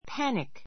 panic A2 pǽnik パ ニ ク 名詞 突然の恐怖 きょうふ 感, パニック, 動揺 どうよう , 大混乱; 経済恐慌 きょうこう Pan The passengers on the sinking ship were in a panic.